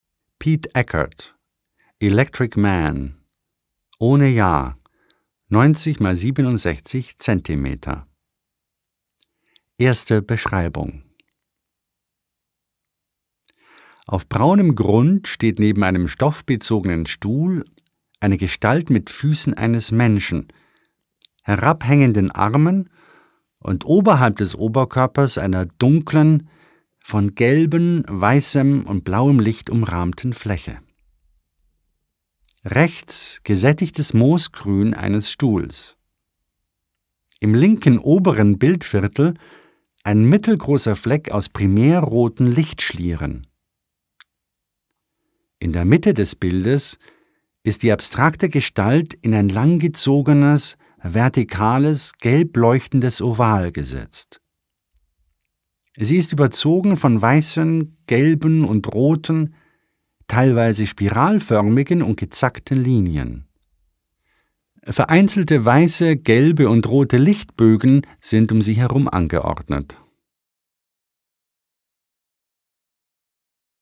Der Text stammt aus dem großartigen Audio Guide zur Ausstellung.